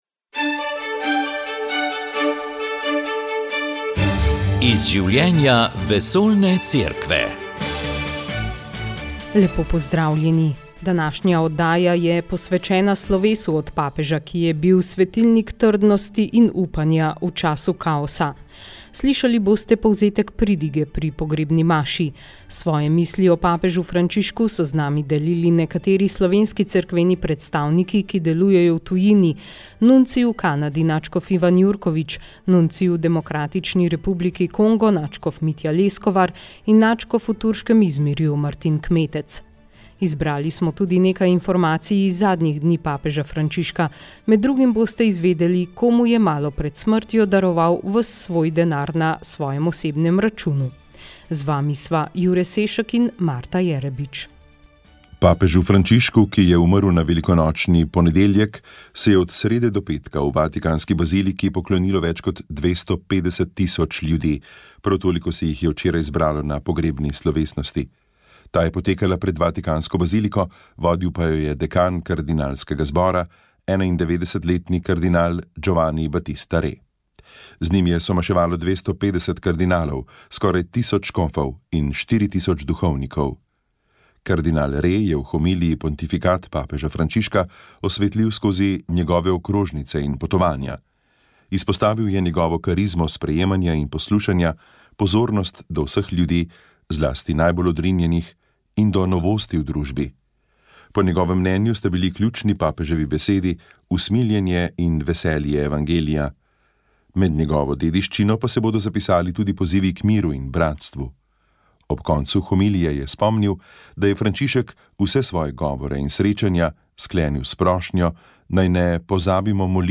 V Sobotnem duhovnem večeru ste slišali duhovni nagovor ljubljanskega pomožnega škofa Franca Šuštarja. Nadaljevali smo z molitvijo prvih večernic 1. postne nedelje in križevim potom, s katerim smo molili po namenu papeža Frančiška za marec: za družine v krizi.